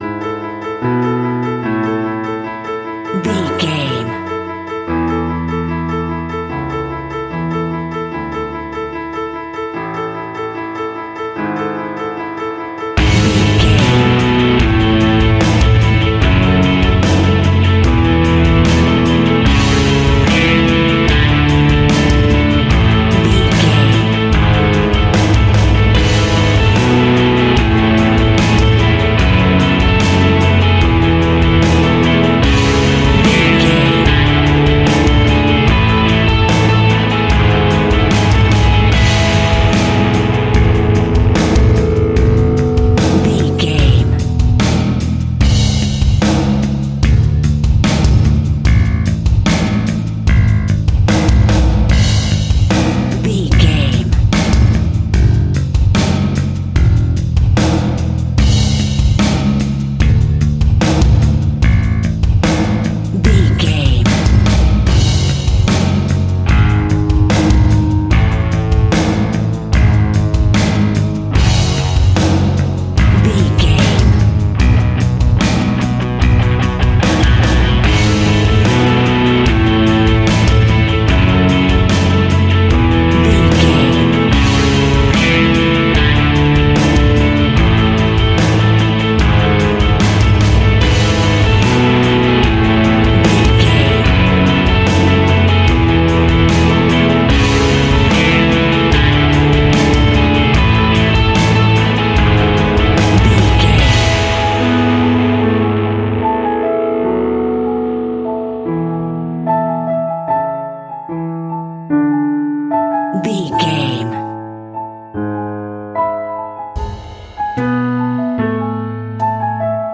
Ionian/Major
calm
melancholic
optimistic
uplifting
piano
electric guitar
bass guitar
drums
distortion
rock music
Instrumental rock
hammond organ